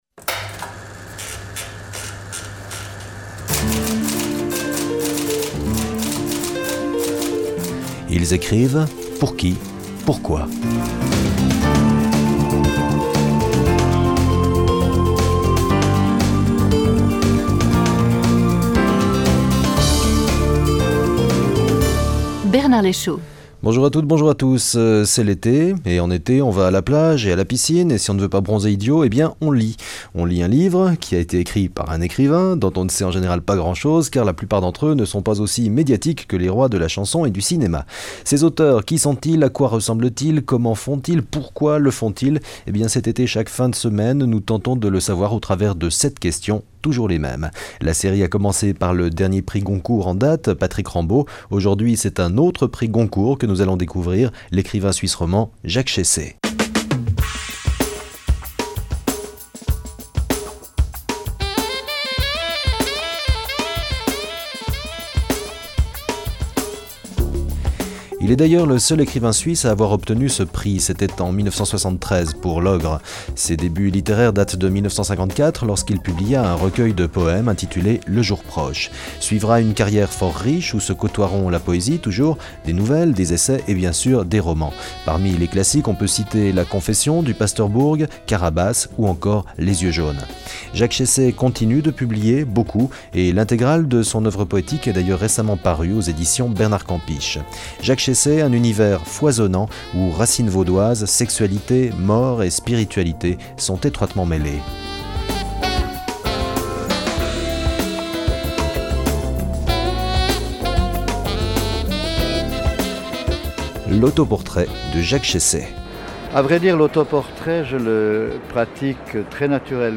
Fichier MP3 Description Emission de radio Ce contenu a été publié sur 01 octobre 2019 - 07:42 Les plus appréciés Les plus discutés Vous pouvez trouver un aperçu des conversations en cours avec nos journalistes ici .